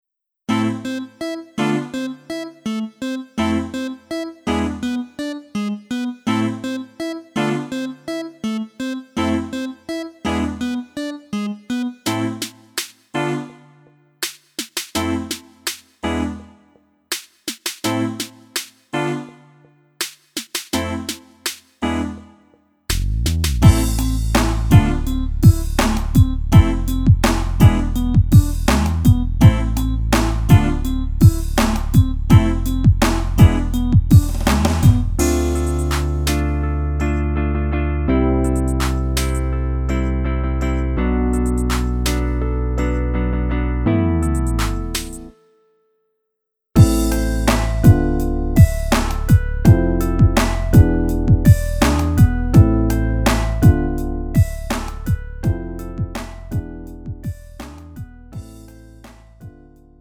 축가 및 결혼식에 최적화된 고품질 MR을 제공합니다!
음정 원키
장르 가요
Lite MR